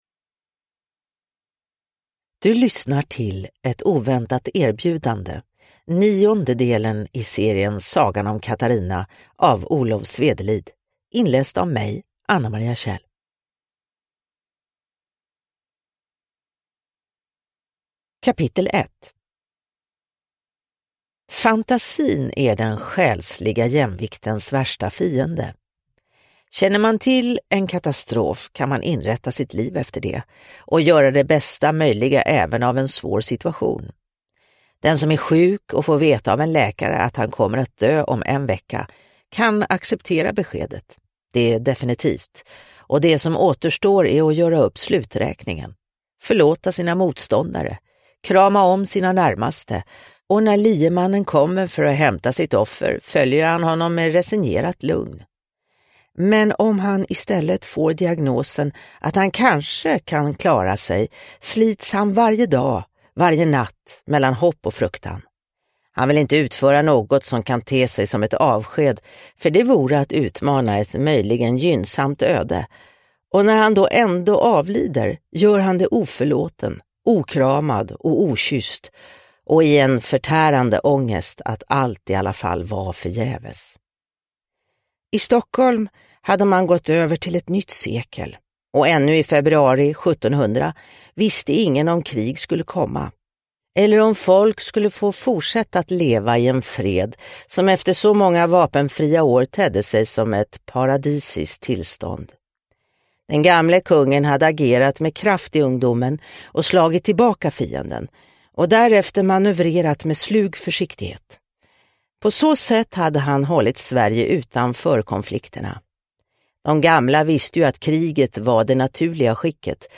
Ett oväntat erbjudande – Ljudbok – Laddas ner